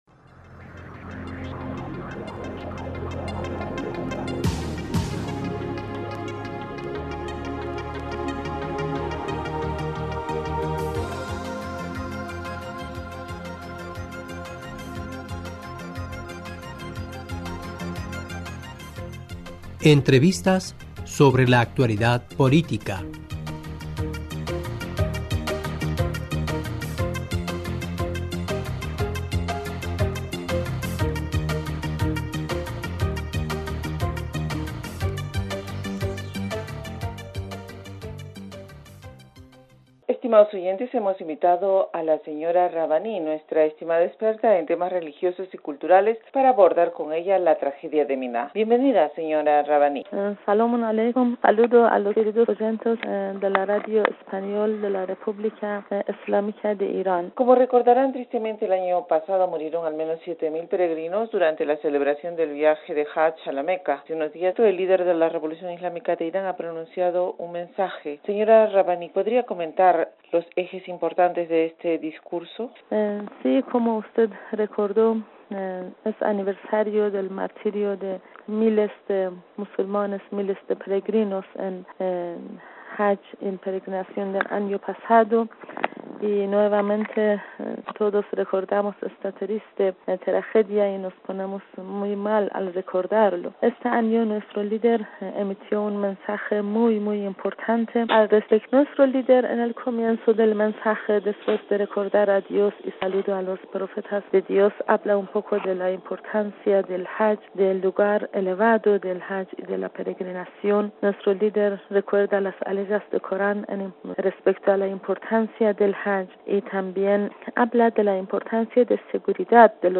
Saludos a los queridos oyentes de la Radio en Español de la República Islámica de Irán.